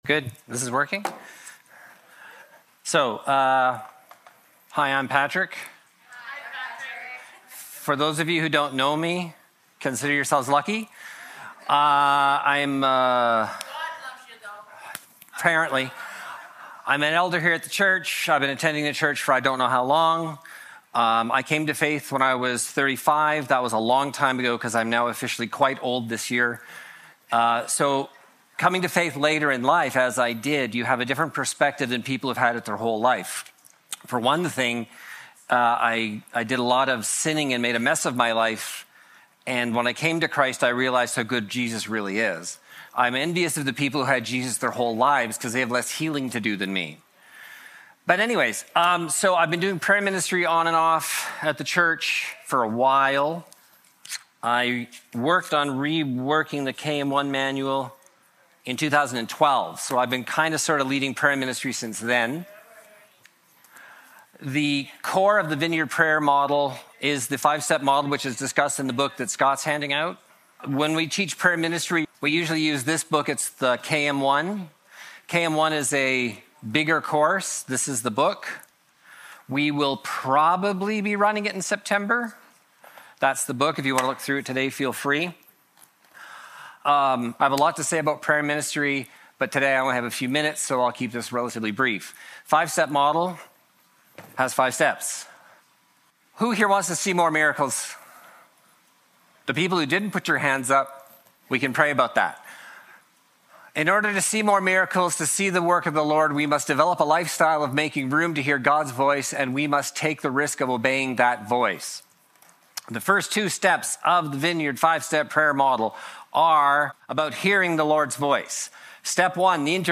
Service Type: Sunday Morning Welcome to week two of a 7-week journey that we are taking together, learning the Vineyard 5-Step prayer model.